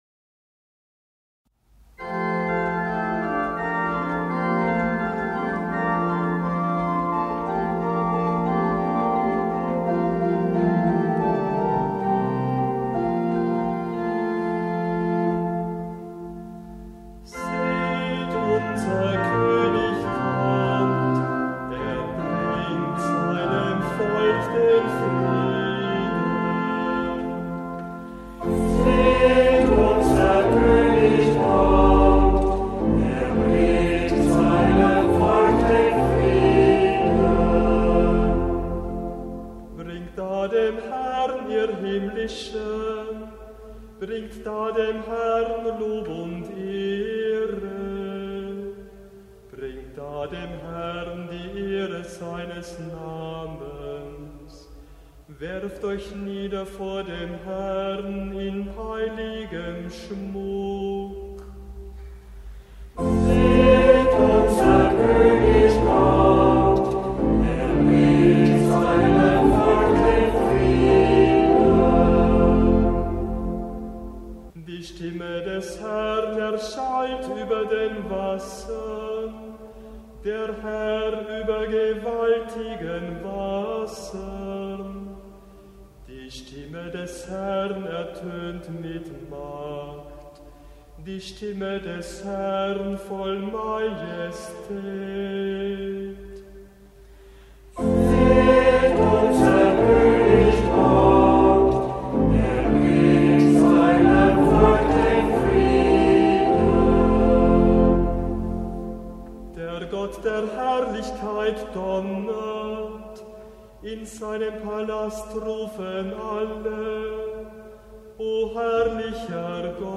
Hörbeispiele aus Kantorenbüchern
Psalmen aus dem Gurker Psalter für Kantor mit Orgel- oder Gitarrenbegleitung finden Sie hier, geordnet nach den Lesejahren ABC und den Festen bzw.